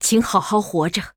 文件 文件历史 文件用途 全域文件用途 Lobato_fw_02.ogg （Ogg Vorbis声音文件，长度1.2秒，118 kbps，文件大小：17 KB） 文件说明 源地址:游戏语音 文件历史 点击某个日期/时间查看对应时刻的文件。 日期/时间 缩略图 大小 用户 备注 当前 2018年11月17日 (六) 03:34 1.2秒 （17 KB） 地下城与勇士  （ 留言 | 贡献 ） 分类:洛巴赫 分类:地下城与勇士 源地址:游戏语音 您不可以覆盖此文件。